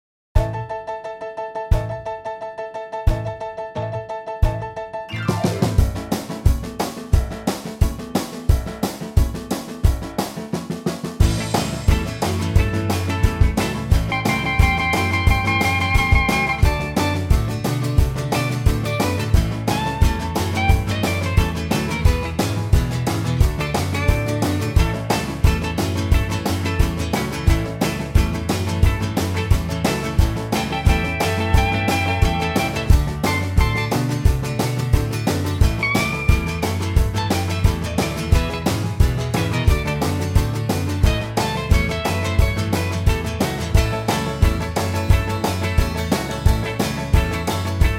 key - A - vocal range - C# to A and blues notes in between!